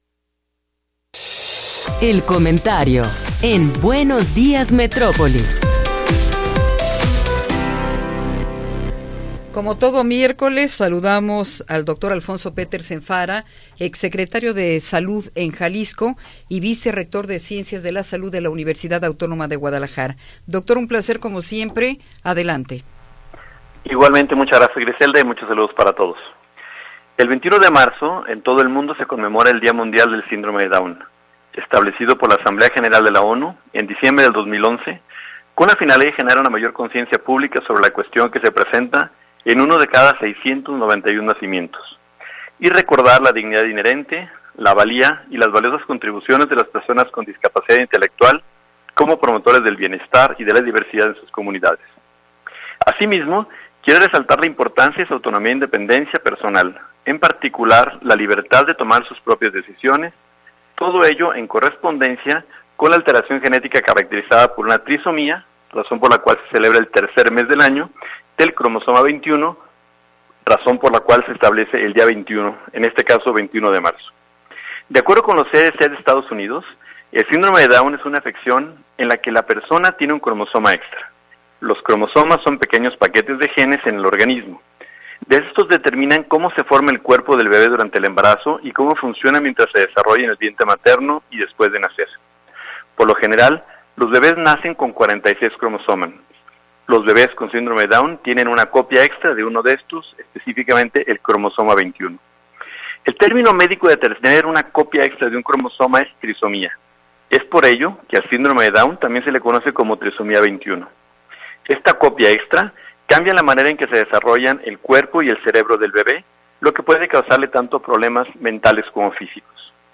Comentario de Alfonso Petersen Farah
El Dr. Alfonso Petersen Farah, vicerrector de ciencias de la salud de la UAG y exsecretario de salud del estado de Jalisco, nos habla sobre el Día Mundial del Síndrome de Down.